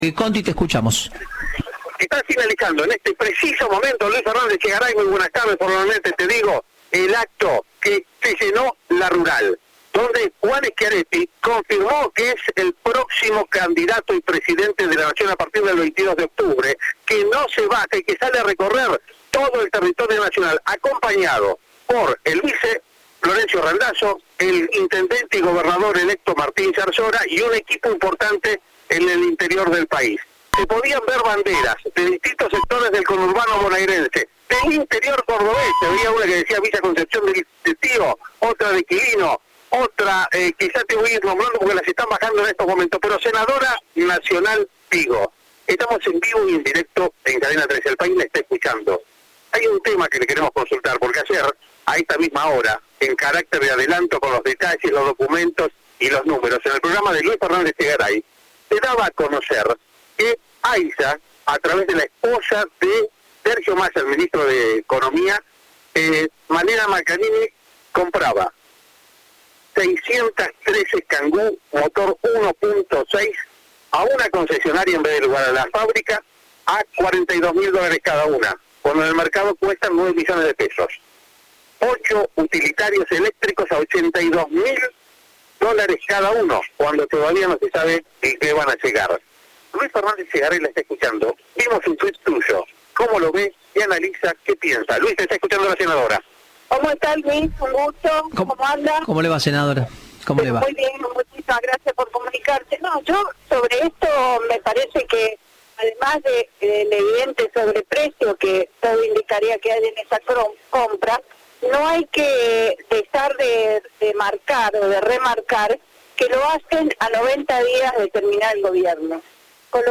La senadora nacional por Córdoba dijo a Cadena 3: “Además del evidente sobreprecio, no hay que dejar de remarcar que lo hacen a 90 días de dejar el Gobierno, de mínima hay una gran irresponsabilidad".